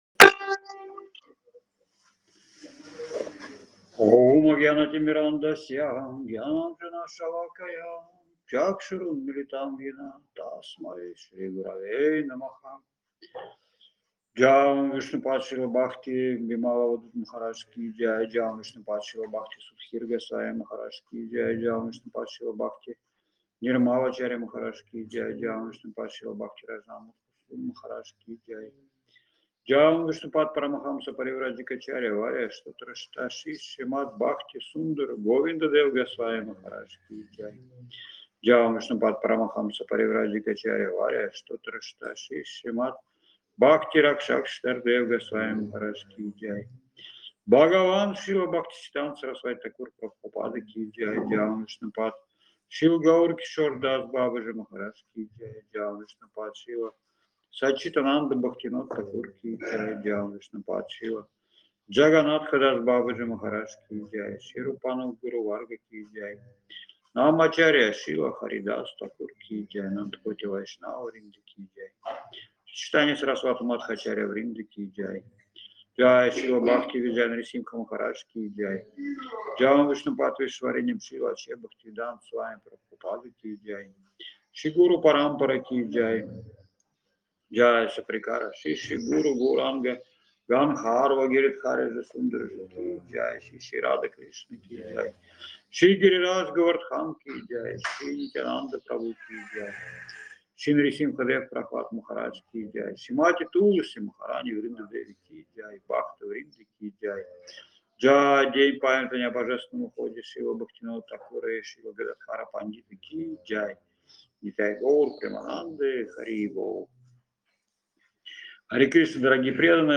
Место: Абхазия
Лекции полностью
Бхаджан